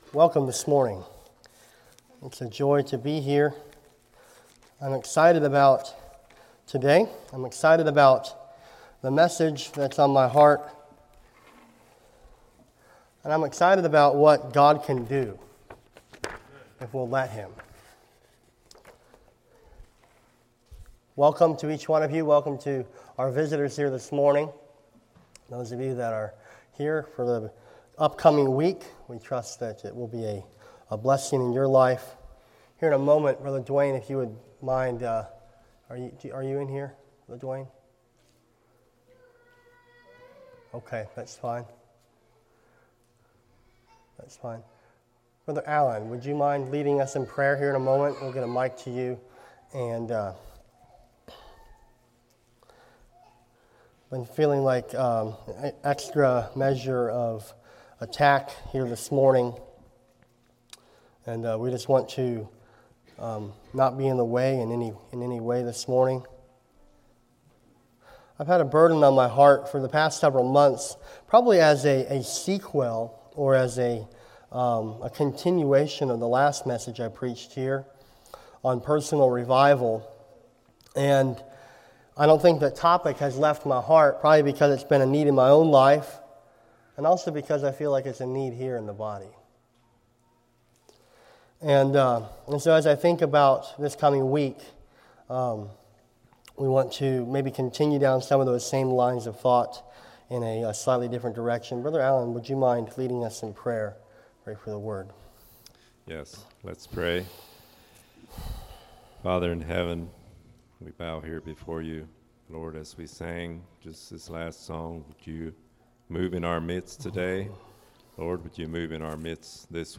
Sermons - Blessed Hope Christian Fellowship